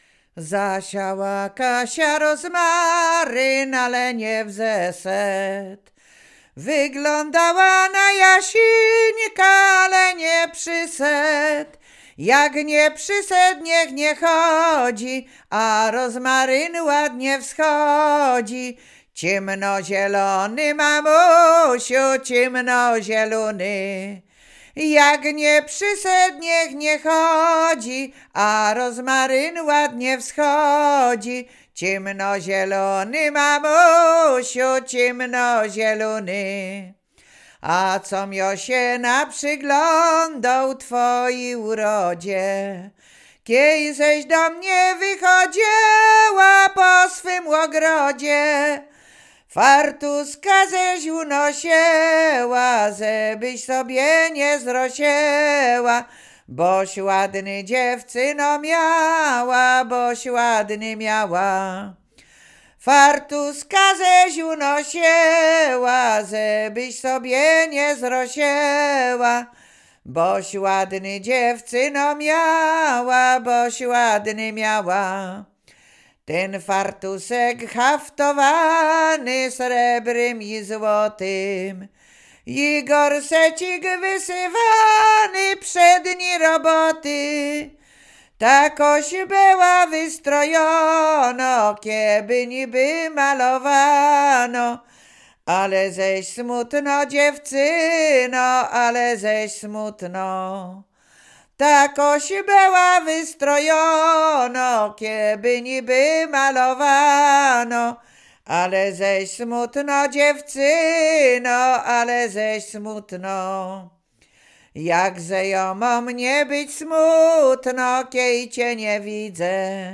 Łowickie
województwo łódzkie, powiat skierniewicki, gmina Lipce Reymontowskie, wieś Drzewce
miłosne liryczne